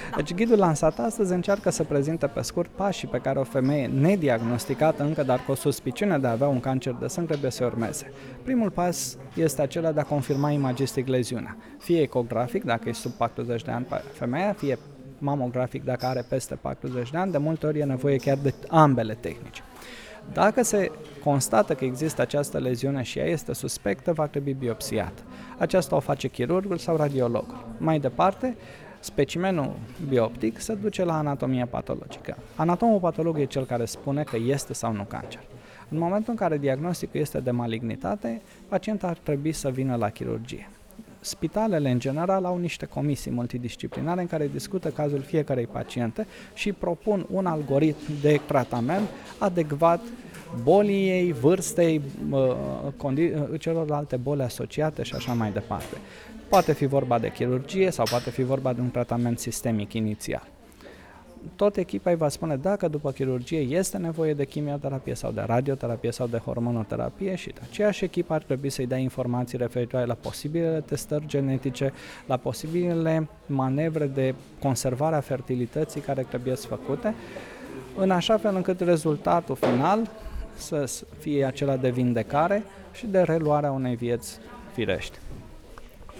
într-o conferinţă de presă